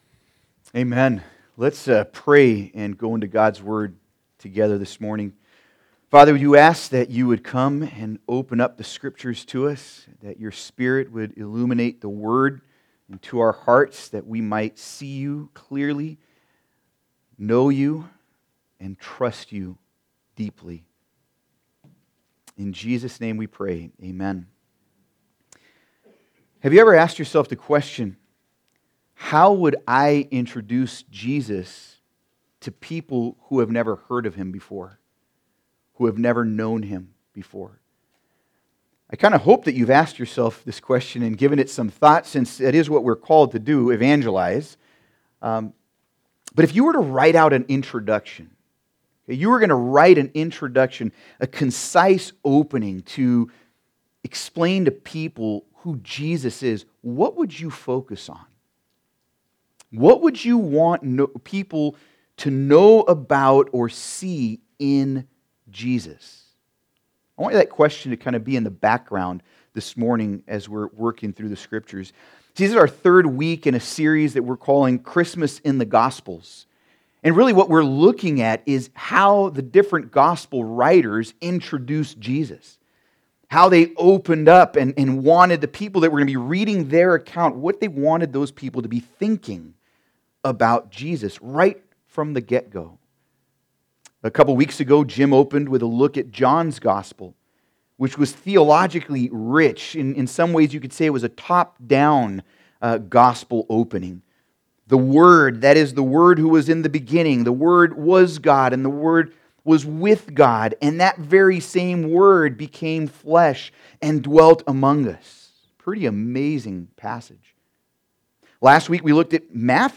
Passage: Mark 1:1-13 Service Type: Sunday Service